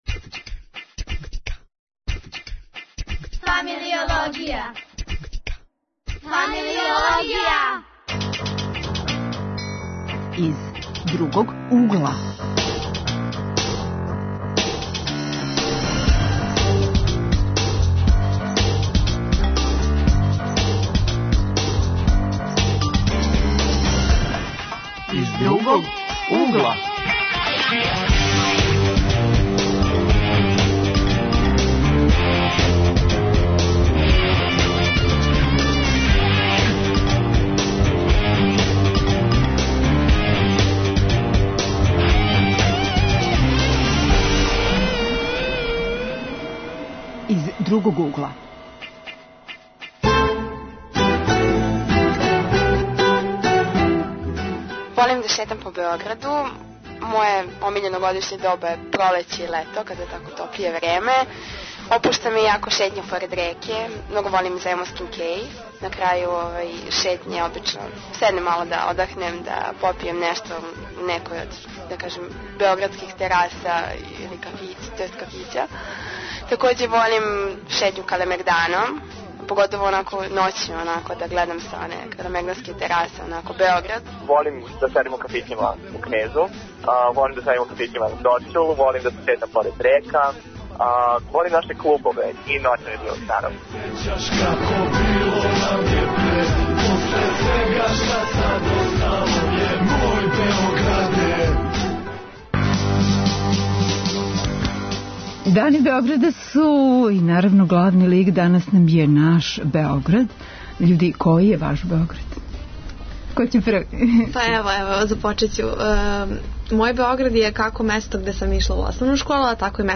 Гости студенти: глумци, спортисти, музичари који дају душу нашем Београду.